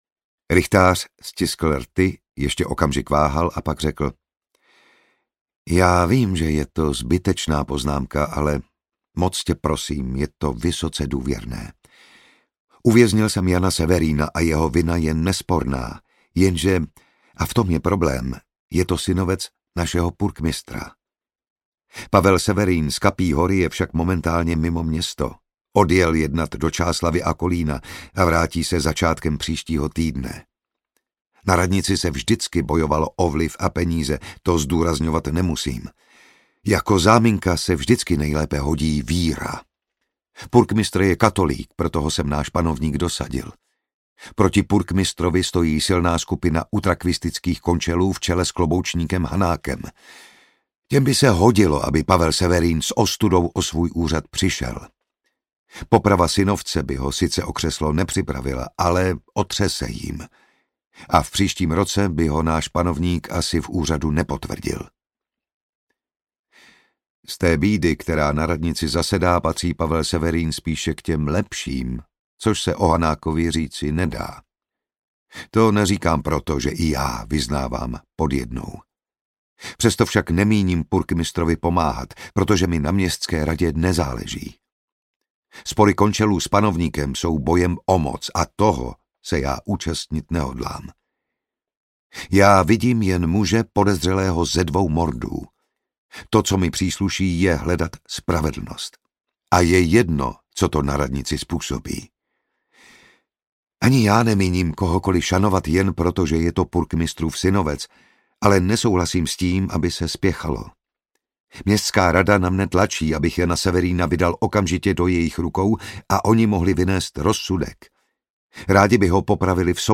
Manželské zrcadlo audiokniha
Ukázka z knihy
• InterpretJan Šťastný
manzelske-zrcadlo-audiokniha